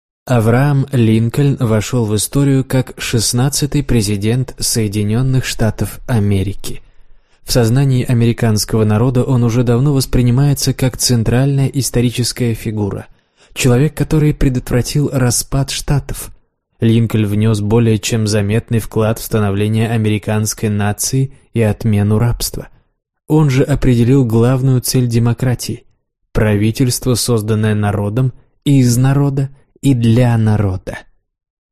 Аудиокнига Авраам Линкольн. Секреты успеха | Библиотека аудиокниг